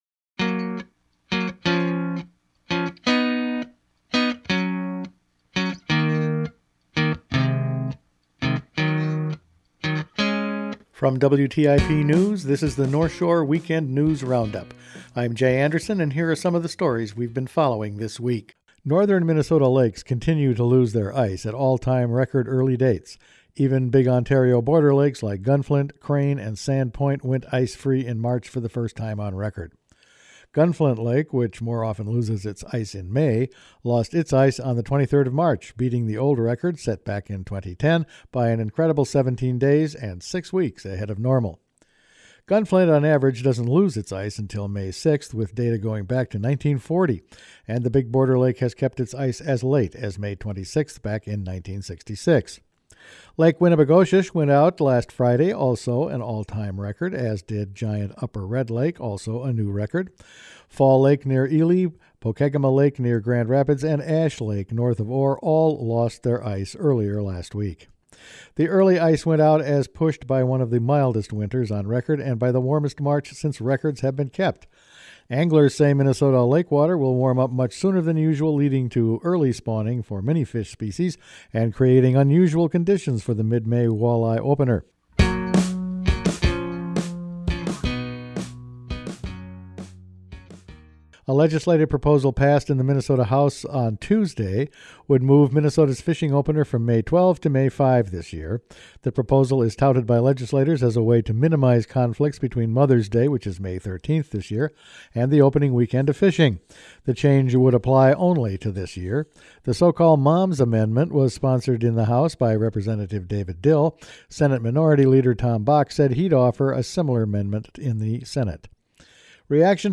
Each weekend WTIP news produces a round up of the news stories they’ve been following this week. Early ice-out on area lakes, an early start to the fishing season, cell towers are back in the news, the Grand Marais Rec Park is geared up for spring, and a wolf season is set…all in this week’s news.